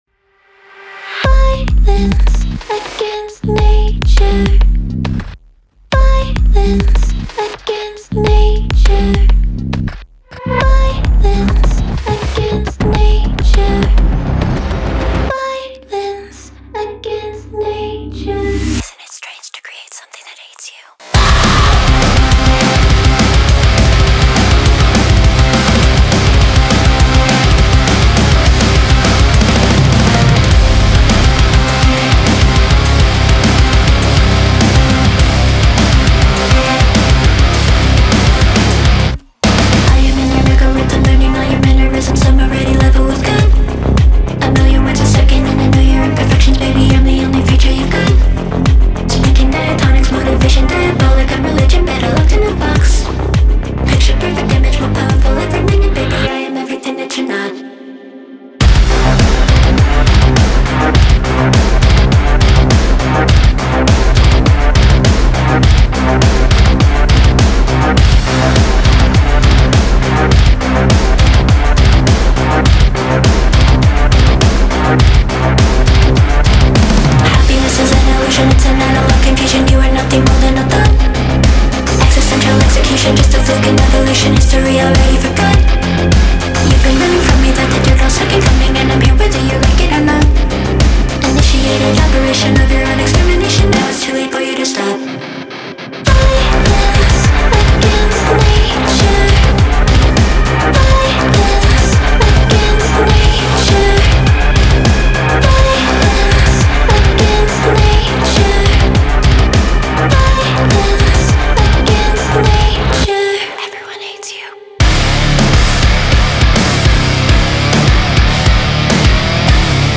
BPM103-103
Audio QualityPerfect (High Quality)
Full Length Song (not arcade length cut)